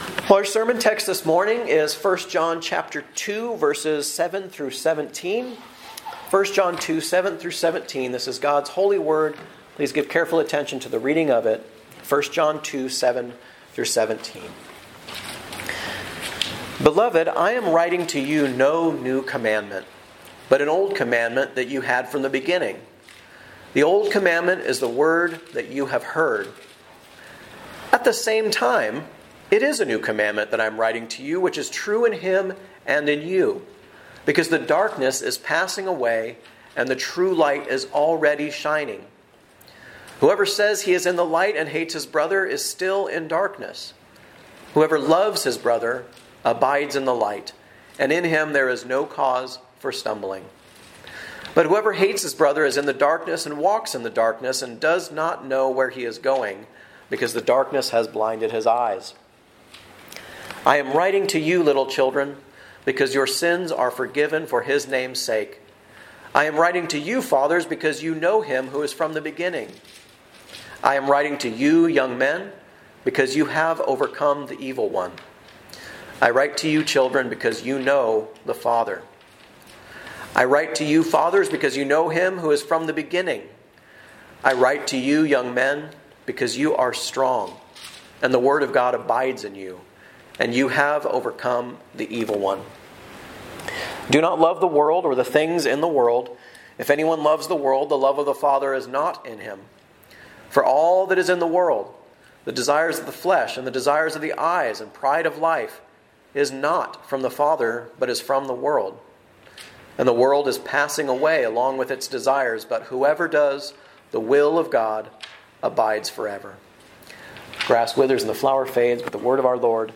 A message from the series "Guest Preacher."